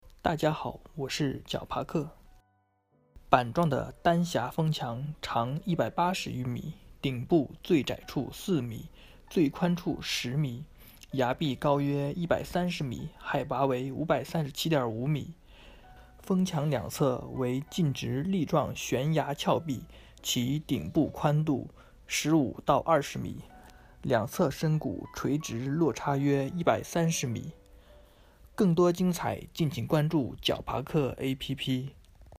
解说词